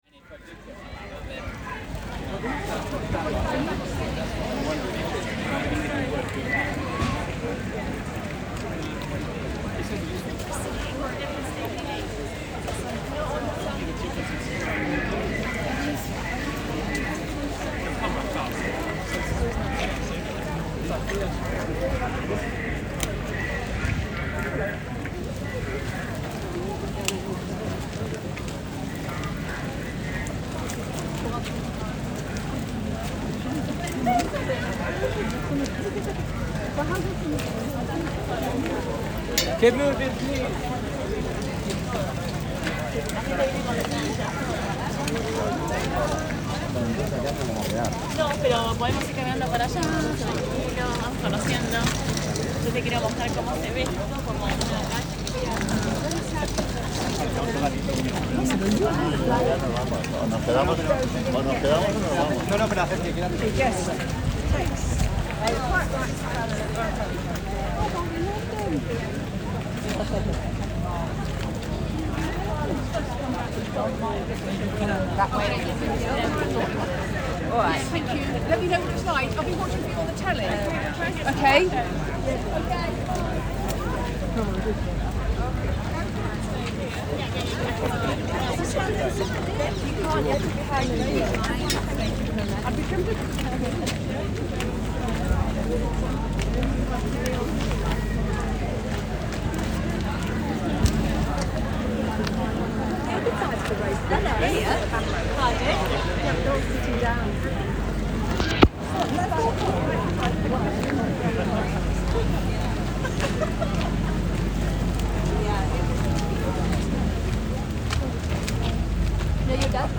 Facebook Twitter Headliner Embed Embed Code See more options A walk down the length of the Mall, London, from Buckingham Palace to Admiralty Arch on the morning of 14th September 2022, about 3 hours before the procession of the coffin of Queen Elizabeth II from Buckingham Palace to lying in state at Westminster Hall. The sound of the many people who had arrived early to watch the procession on a grey, overcast morning.